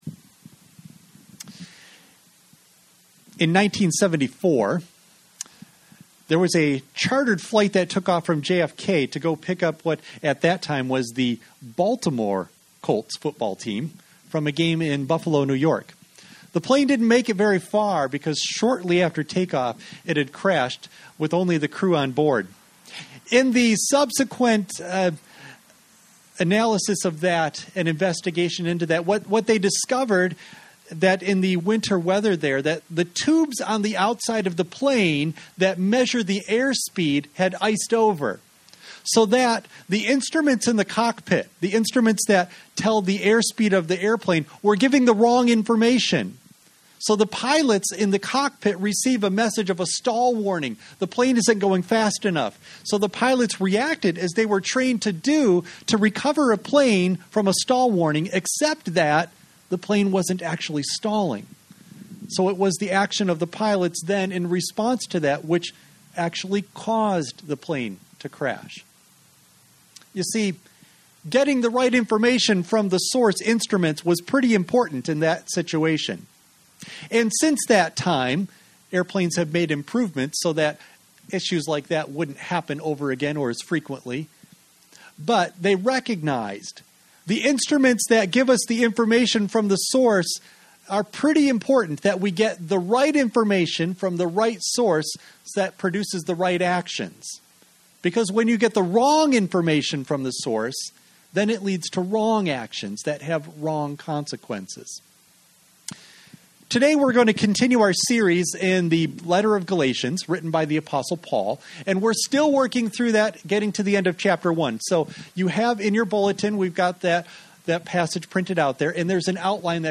You may download and print the BULLETIN for this service as well as sermon NOTES for children from the Download Files section at the bottom of this page Worship Service September 13 Audio only of message